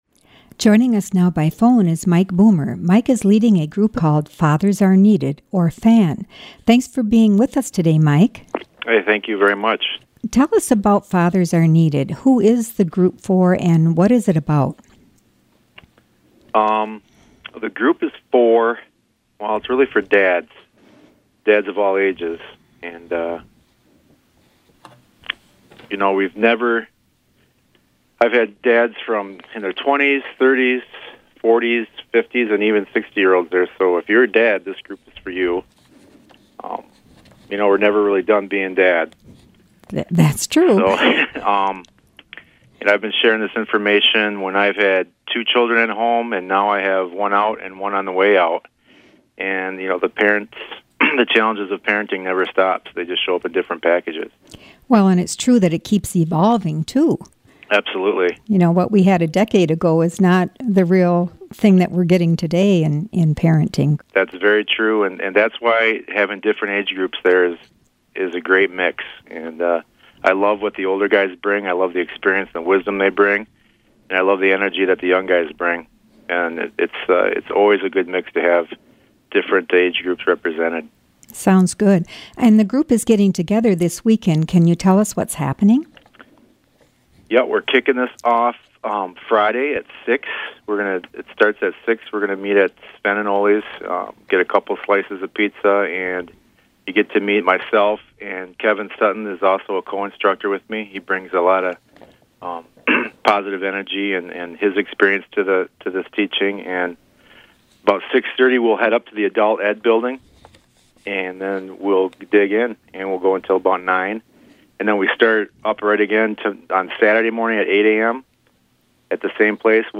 interview
recorded live on the A.M. Community Calendar program